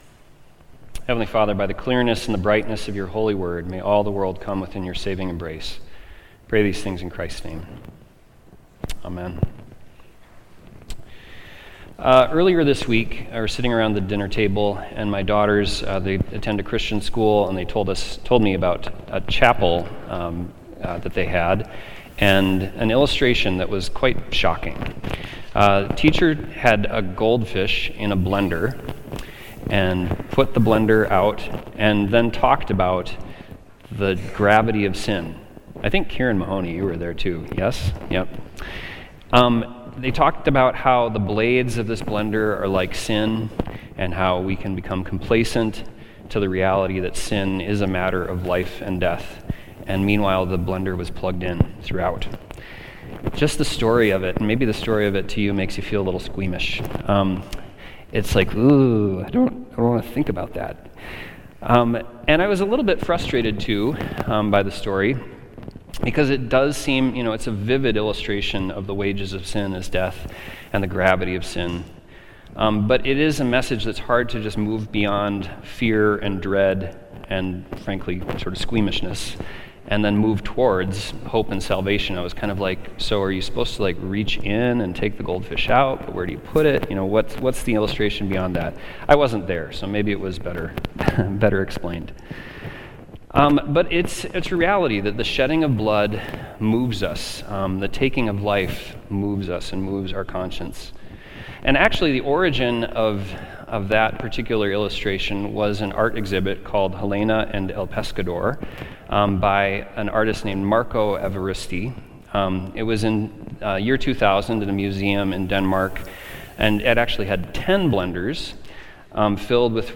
Sunday Worship–April 6, 2025
Sermons